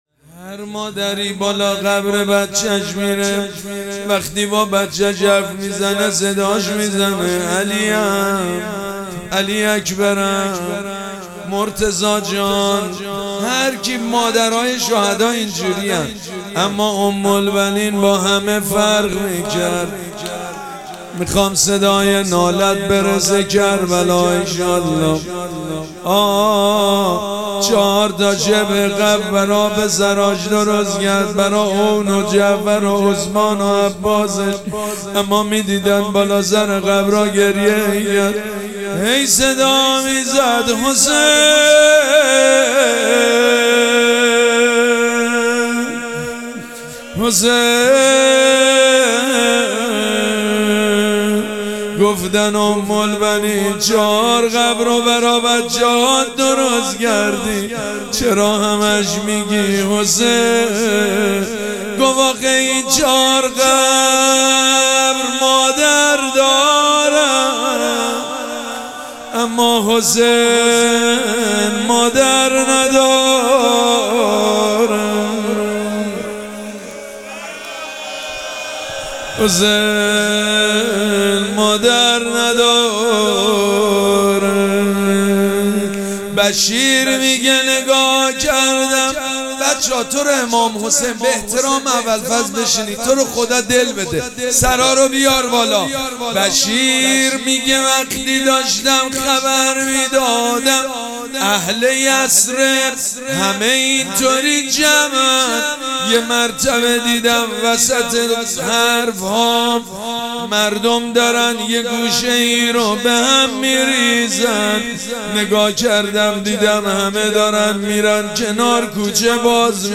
شب چهارم مراسم عزاداری اربعین حسینی ۱۴۴۷
روضه
مداح